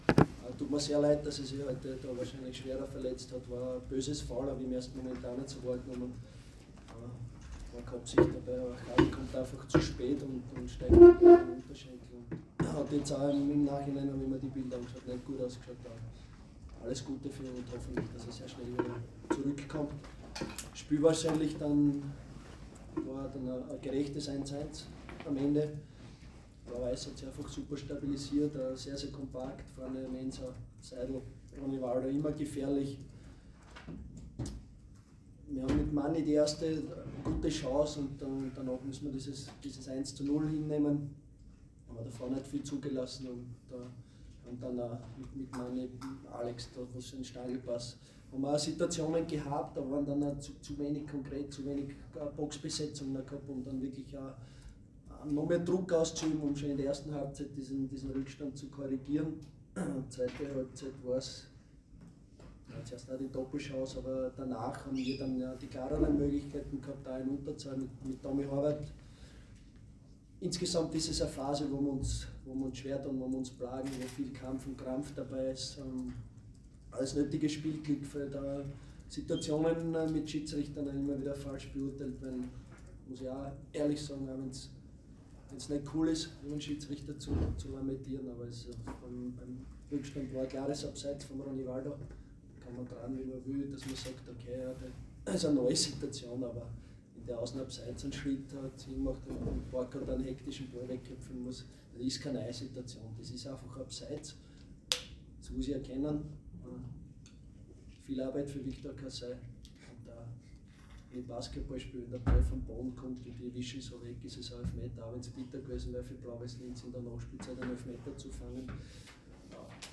Cheftrainer Christian Ilzer bei der Pressekonferenz nach dem Unentschieden in Linz.